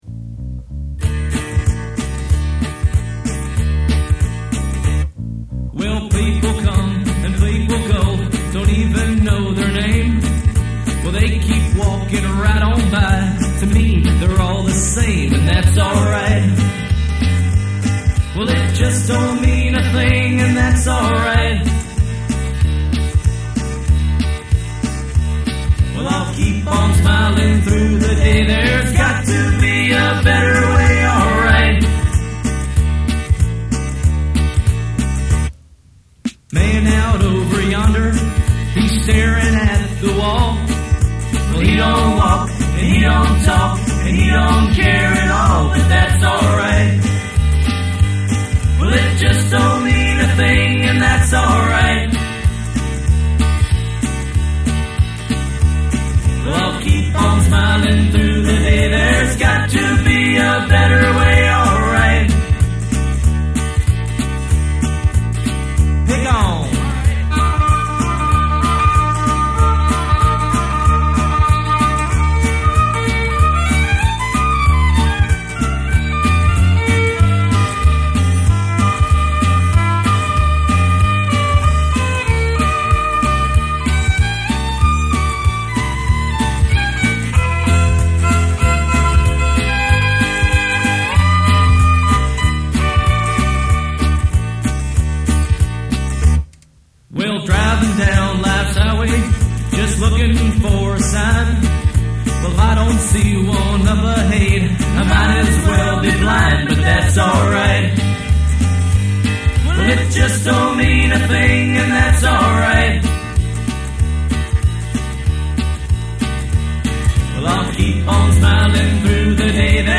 • (D) Sang Lead Vocals
• (E) Sang Backing Vocals
• (F) Played Drums
• (G) Played Guitars
• (H) Played Keyboards